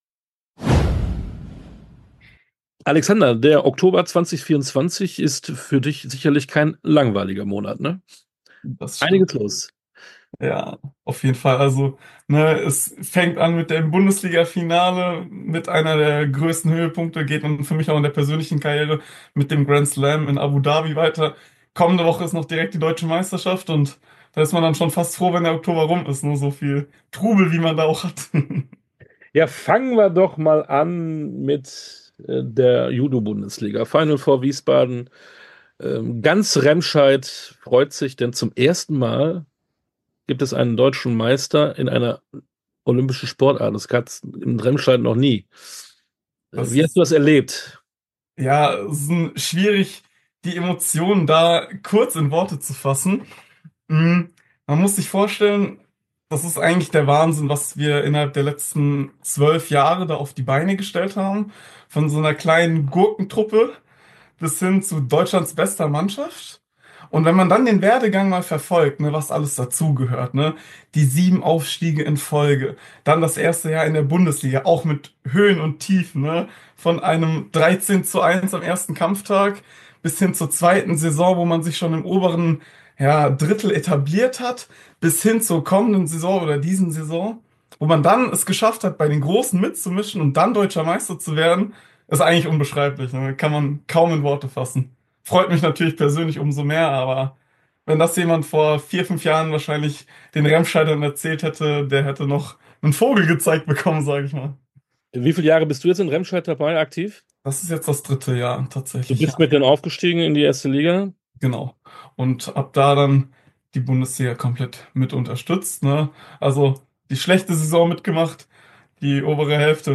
Interview komplett